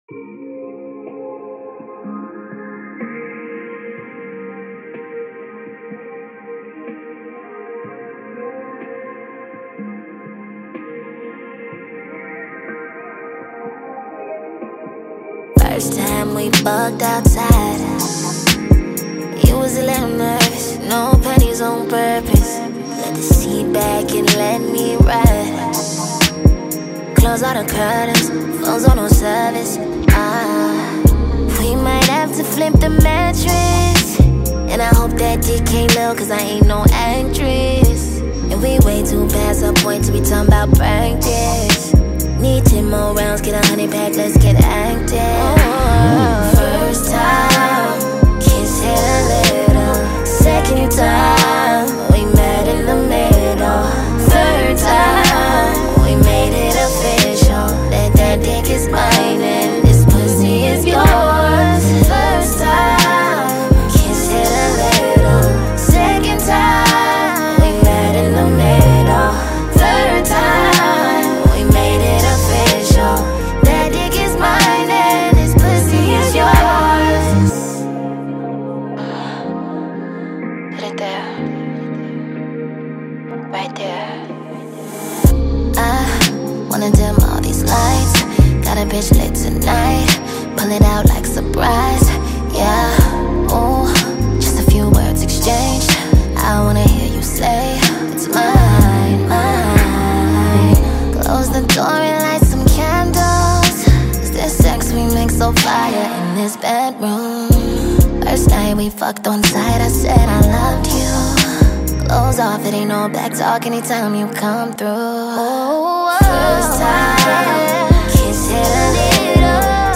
a smooth and engaging tune